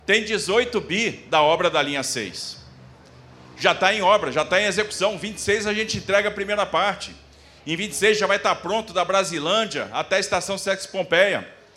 Declarações foram feitas pelo Governador Tarcísio de Freitas em cerimônia de assinatura de aditivo para prolongar a linha 4 para Taboão da Serra